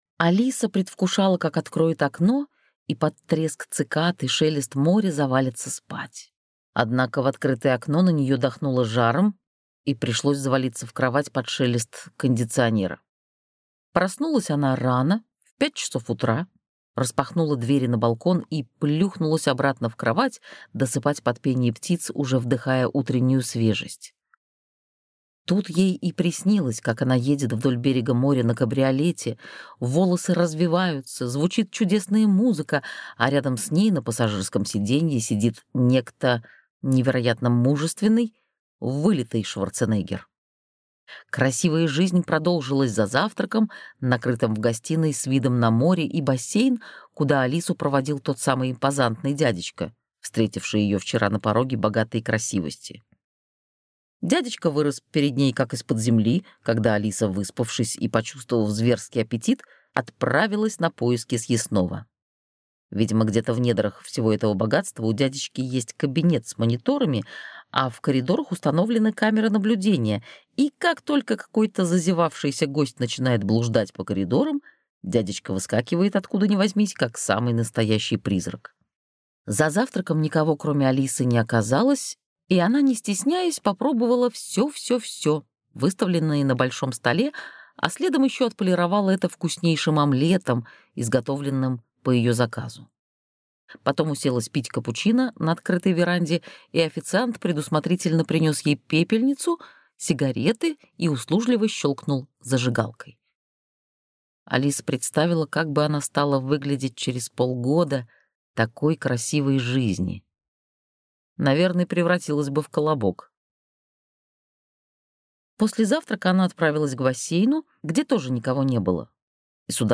Аудиокнига Красивая жизнь глазами инженера первой категории | Библиотека аудиокниг